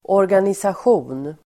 Uttal: [årganisasj'o:n]